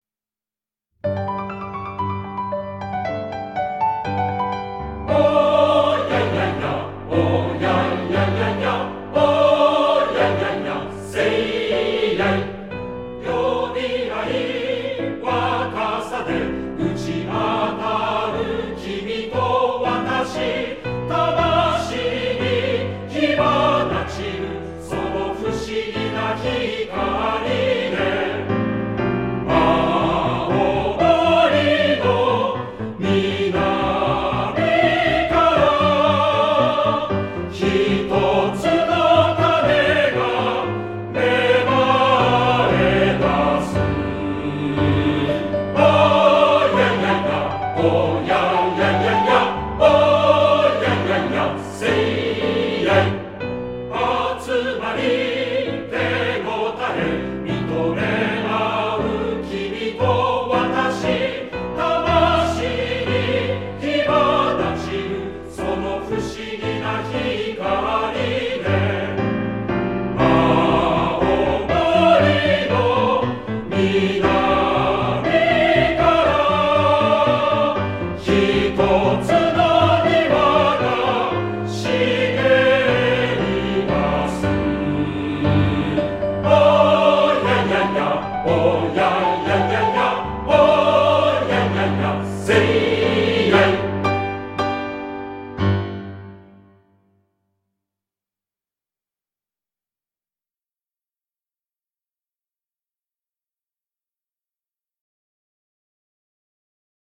・斉唱（ピアノ伴奏）バージョン